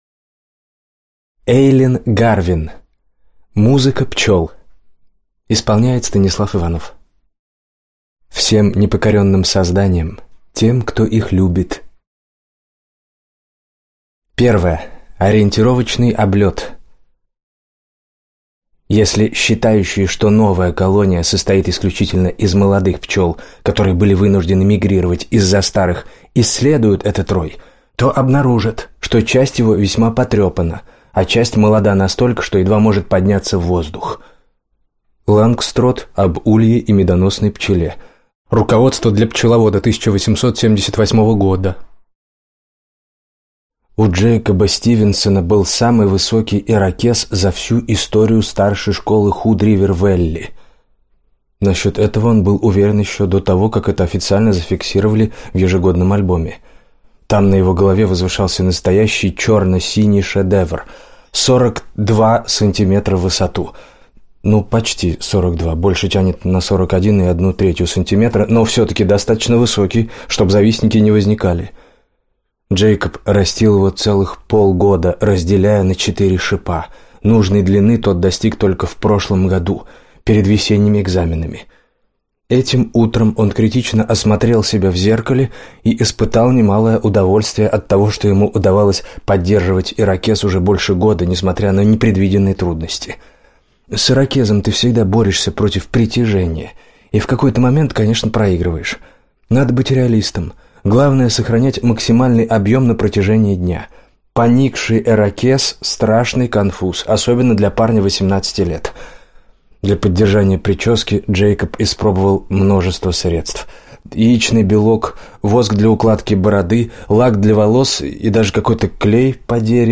Аудиокнига Музыка пчел | Библиотека аудиокниг
Прослушать и бесплатно скачать фрагмент аудиокниги